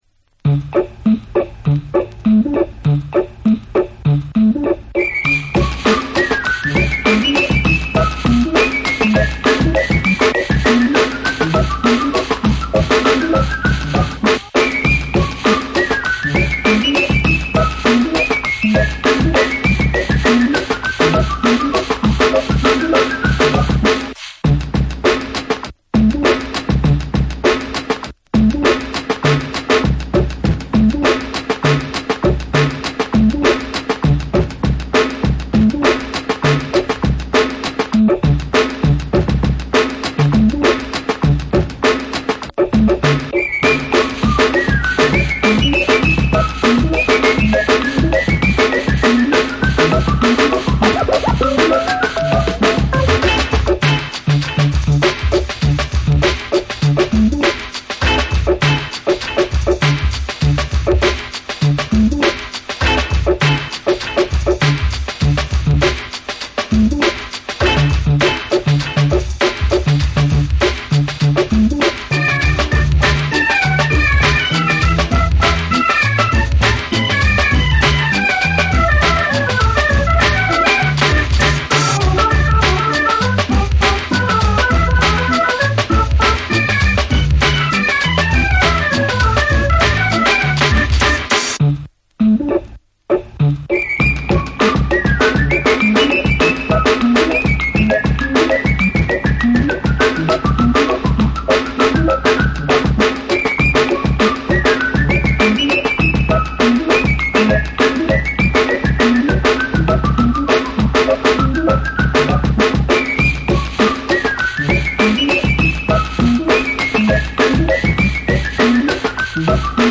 ファニーな雰囲気を醸し出すメロディーが印象的な
アグレシッブに乱れ打つドラムブレイクに、唸りまくるベースラインや大ネタが飛び交う高速ファンキー・ブレイクビーツ
JAPANESE / BREAKBEATS